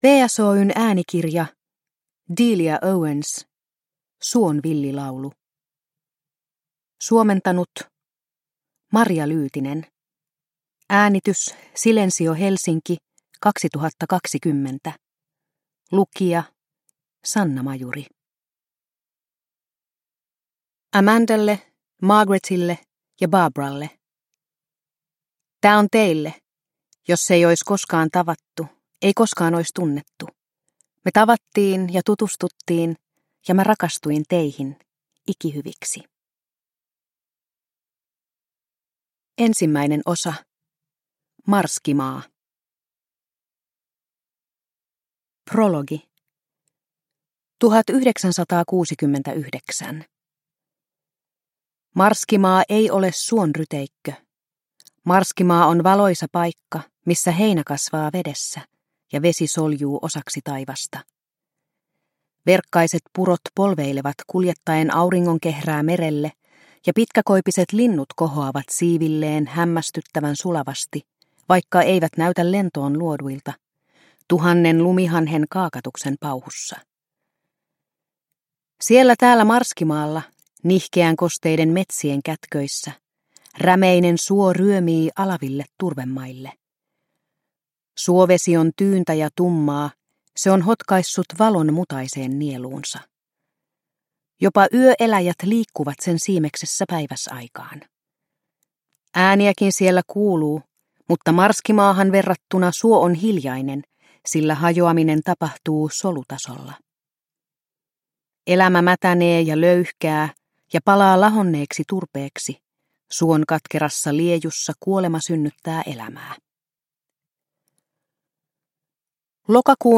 Suon villi laulu – Ljudbok – Laddas ner